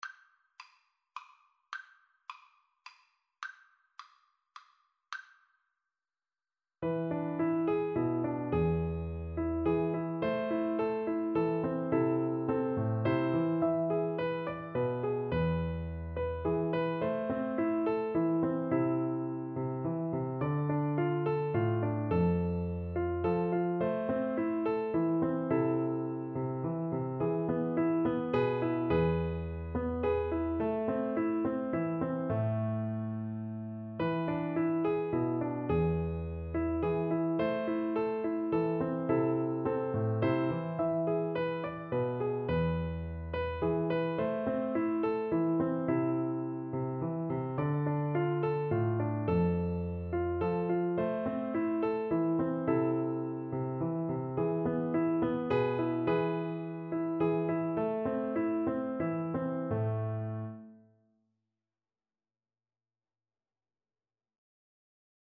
Slow Waltz = c. 106
3/4 (View more 3/4 Music)
A minor (Sounding Pitch) (View more A minor Music for Voice )
Traditional (View more Traditional Voice Music)